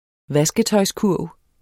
Udtale [ ˈvasgətʌjs- ]